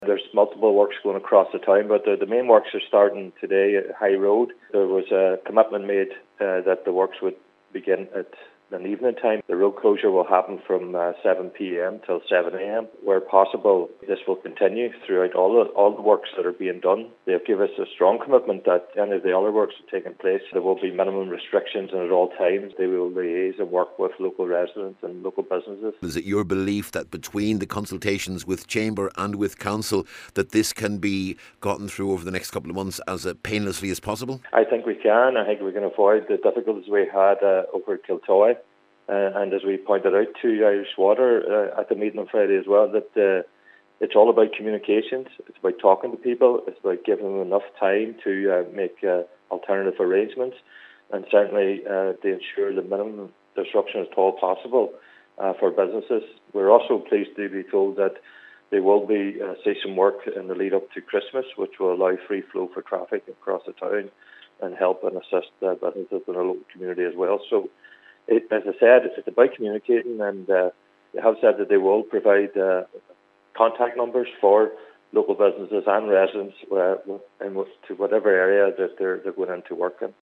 Cllr Gerry Mc Monagle says increased communication is welcome………….